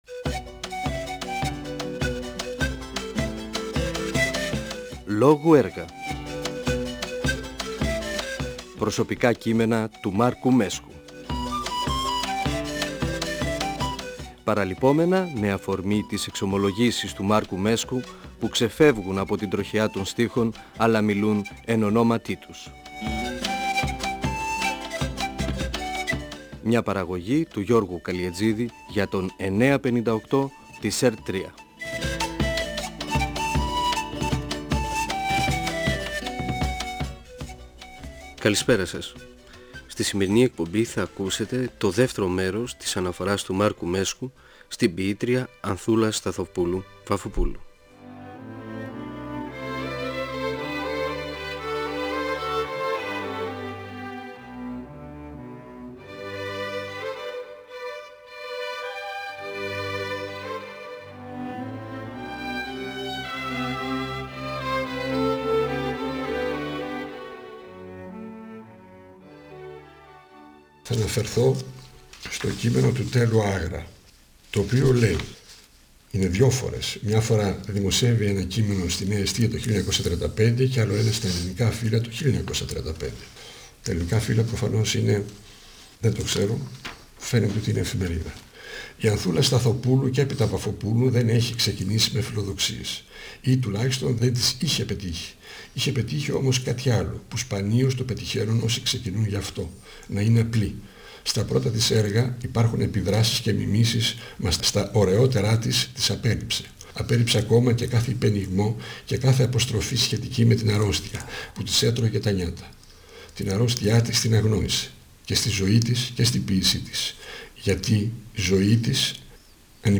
Ο ποιητής και δοκιμιογράφος Μάρκος Μέσκος (1935-2019) μιλά για την ποιήτρια Ανθούλα Σταθοπούλου-Βαφοπούλου (εκπομπή 2η), για το έργο της, για τις γνώμες των κριτικών, για το «ποιητικό κλίμα» της εποχής της, για το προσωπικό της δράμα. Διαβάζει ποιήματά της και την κριτική τού Τέλλου Άγρα για την ποίησή της.
Νεφέλη, 2000).ΦΩΝΕΣ ΑΡΧΕΙΟΥ του 958fm της ΕΡΤ3.